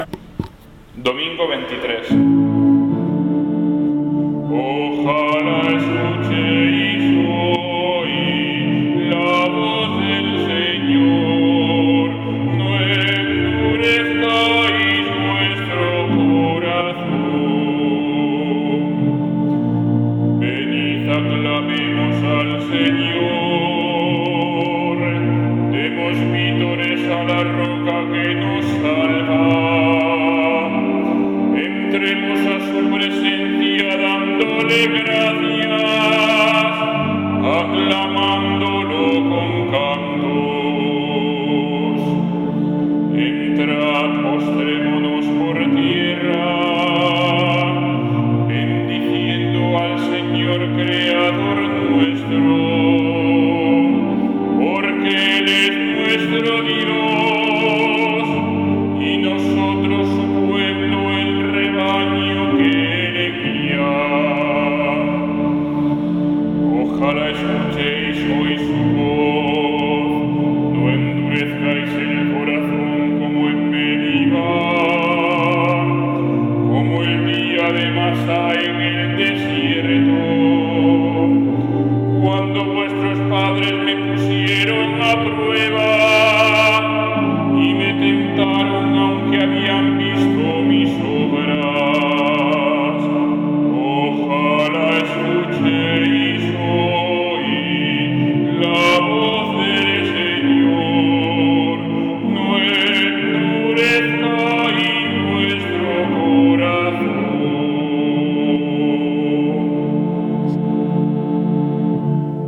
Salmo Responsorial [2.811 KB]